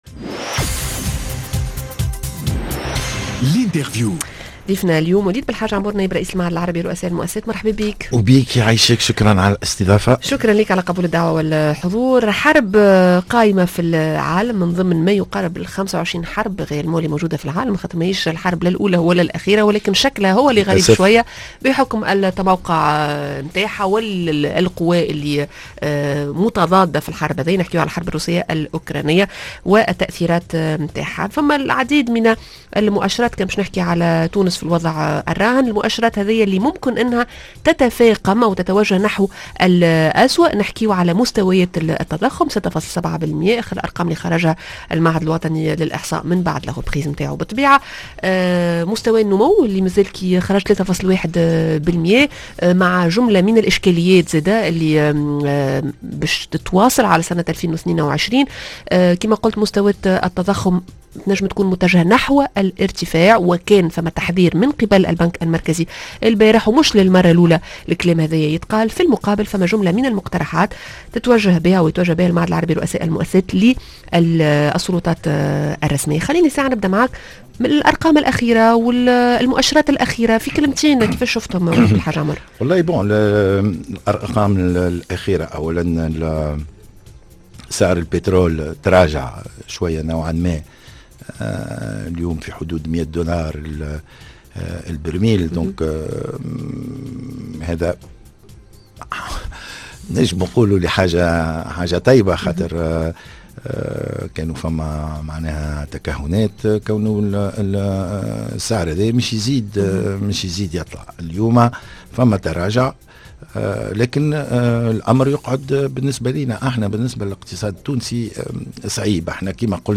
L'interview: تأثير الحرب الروسية الأوكرانية انّجموا نتفاداوه.